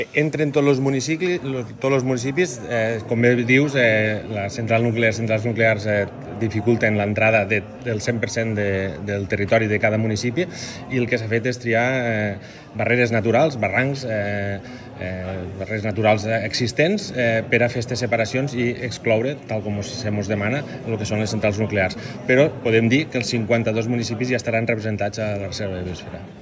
Ivan Garcia, el president del COPATE